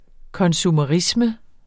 Udtale [ kʌnsumʌˈʁismə ]